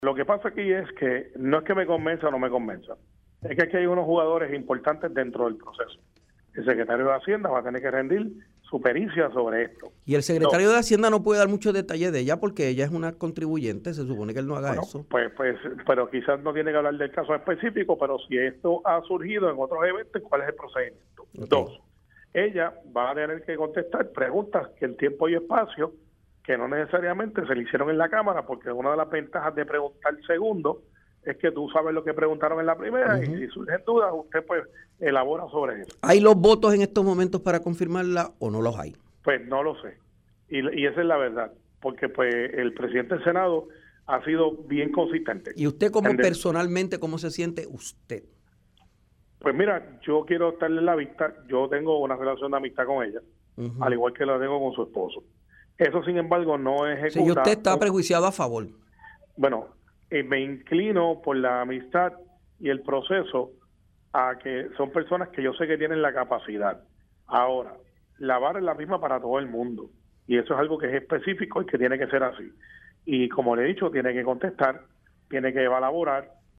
Pues no lo sé (si hay los votos). Y esa es la verdad. Porque el presidente del Senado ha sido bien consistente. […] Yo quiero estar en la vista. Yo tengo una relación de amistad con ella, al igual que la tengo con su esposo. […] Me inclino por la amistad y el proceso a que son personas que yo sé que tienen la capacidad. Ahora, la vara es la misma para todo el mundo. Y eso es algo que es específico y que tiene que ser así. Y como le he dicho, tiene que contestar, tiene que elaborar“, indicó Ríos en entrevista para Tiempo Igual.